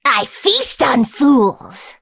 willow_kill_vo_08.ogg